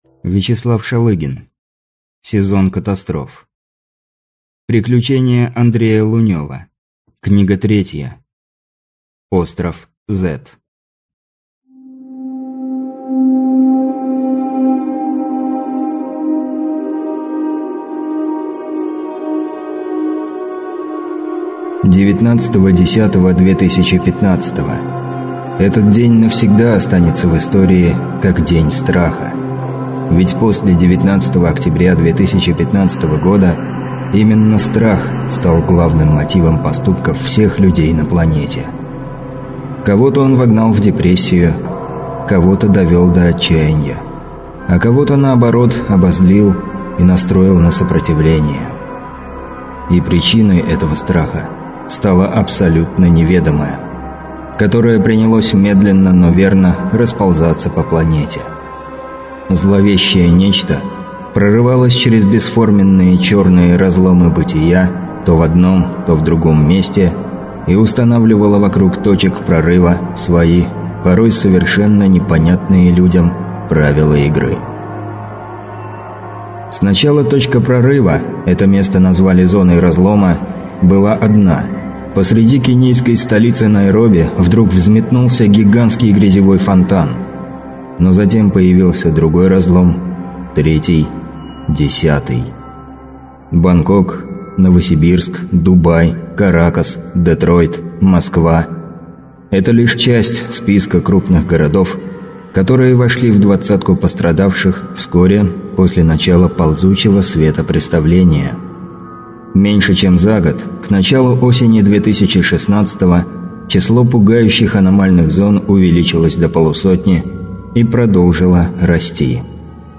Аудиокнига Остров Z | Библиотека аудиокниг